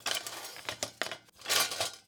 SFX_Cooking_Utensils_01.wav